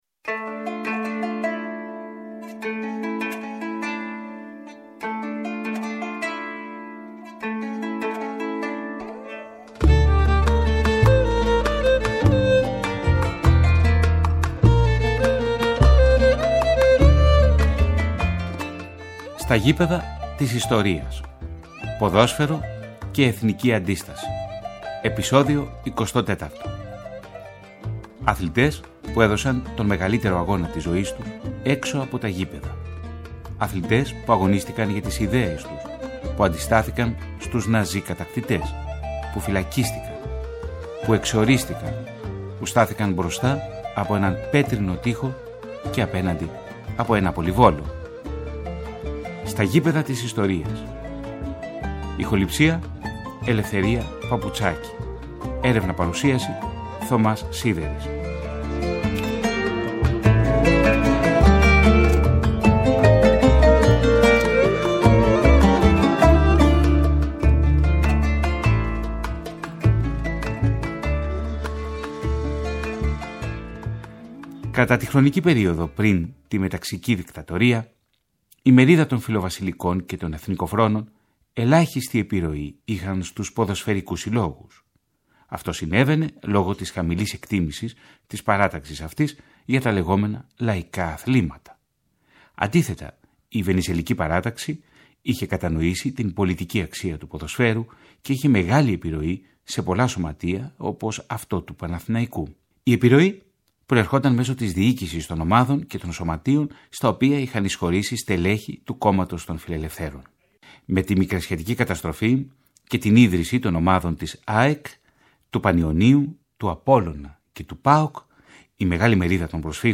Ένα συγκλονιστικό ραδιοφωνικό ντοκιμαντέρ σε δύο μέρη, που ξετυλίγει την αντιστασιακή δράση συλλογικών φορέων και αθλητών την περίοδο της Κατοχής και του Εμφυλίου. Τα πρόσωπα και τα γεγονότα συνθέτουν έναν καμβά όπου το ποδόσφαιρο συναντά την ανθρωπιά και την αλληλεγγύη.